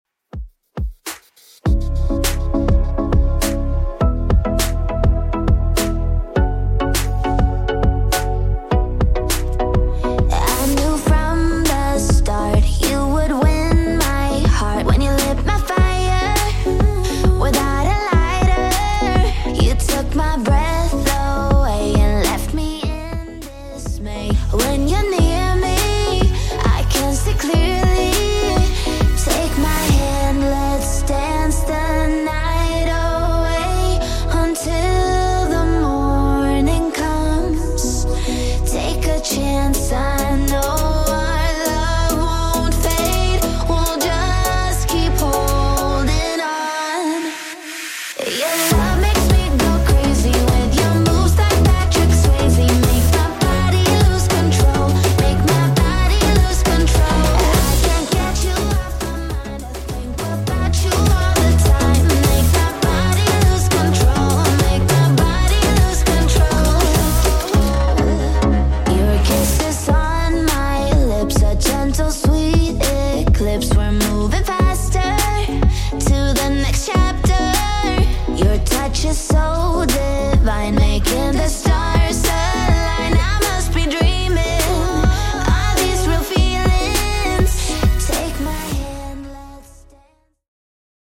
Genre: 80's
BPM: 116